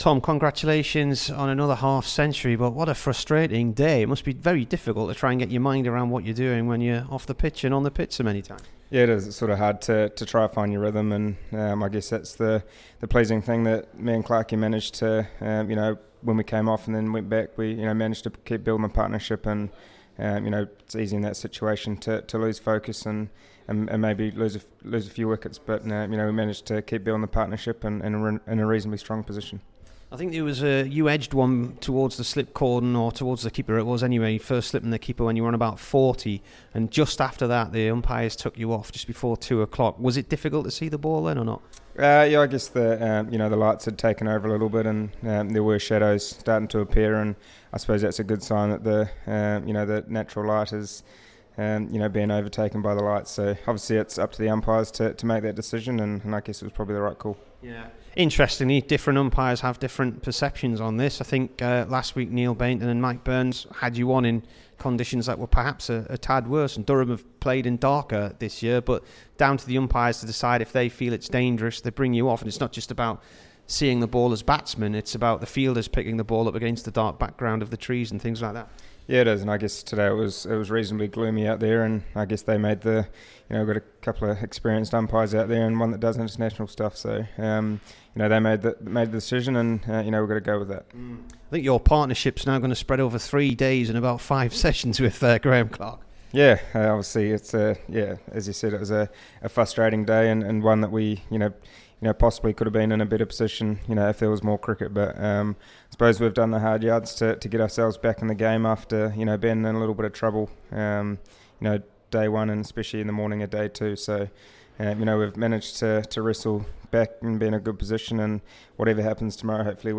TOM LATHAM INT
HERE'S THE DURHAM BATSMAN AFTER REACHING 64* ON DAY 3 OF A RAIN-AFFECTED GAME AGAINST KENT IN CHESTER-LE-STREET.